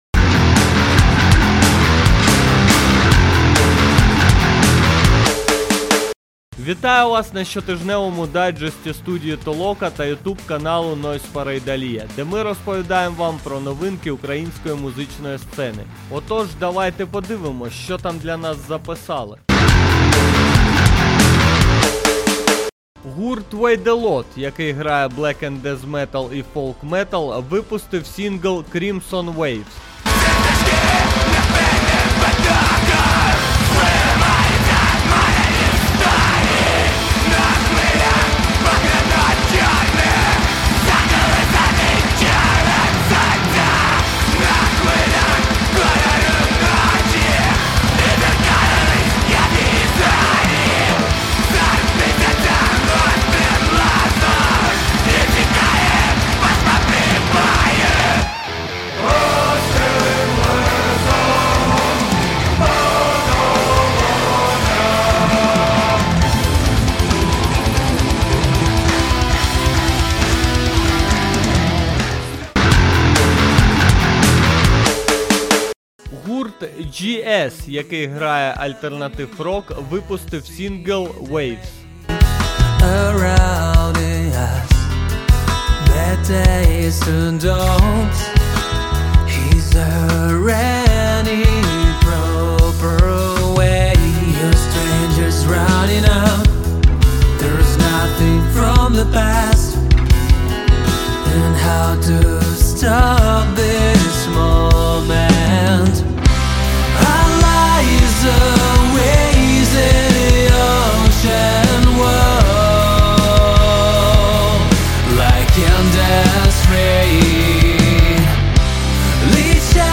Стиль: Подкаст